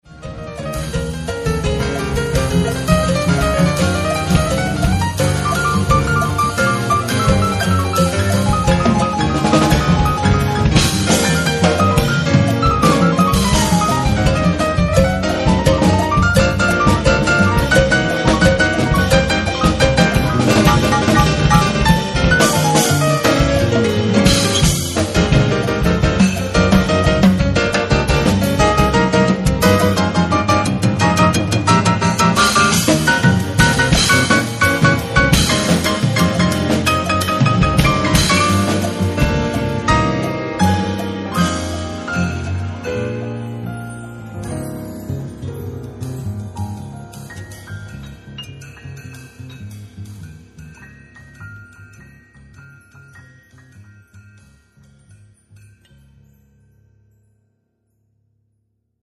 Registrato a Teramo nel Marzo 2003
Pianoforte
Basso elettrico e acustico
Batteria e tabla